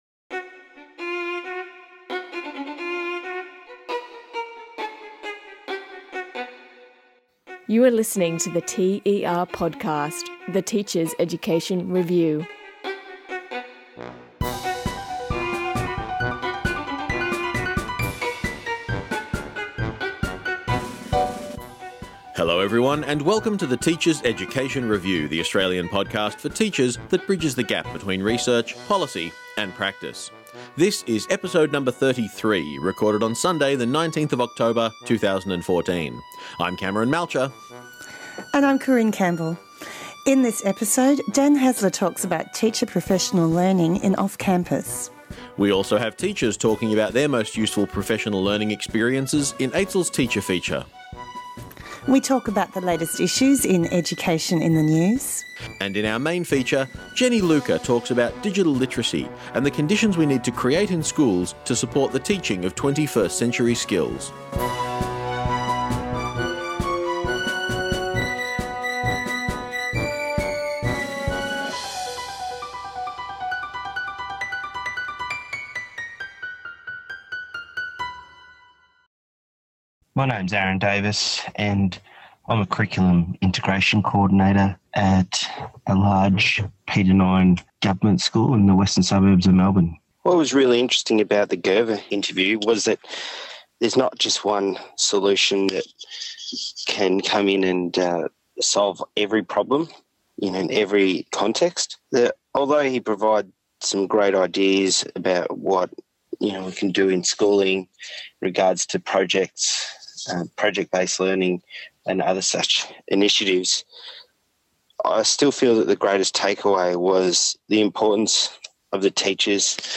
To hear my interview, go to 40min 19sec in when it begins.